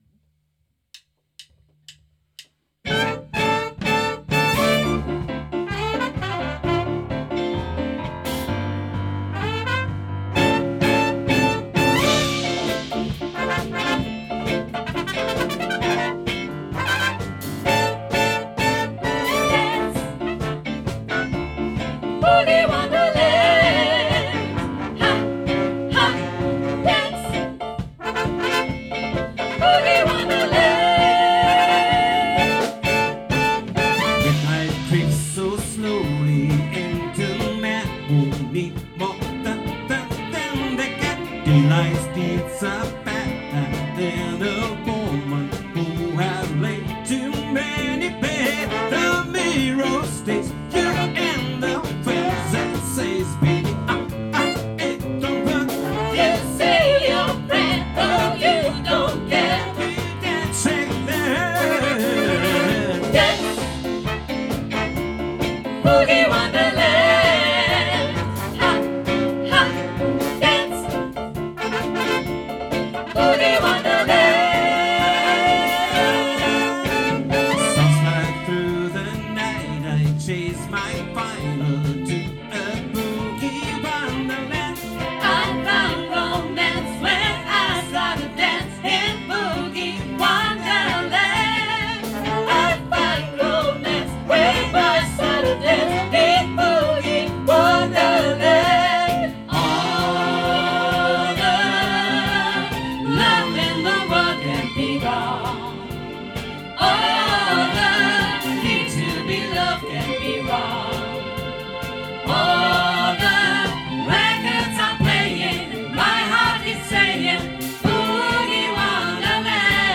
· Genre (Stil): Soul
· Kanal-Modus: mono · Kommentar